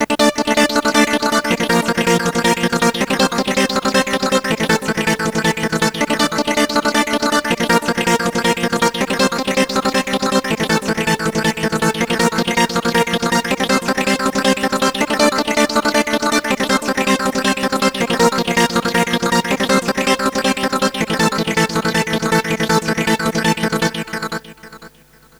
ちなみにアタックの強いブラス音を「スタブ」といいます。
mainstab.aiff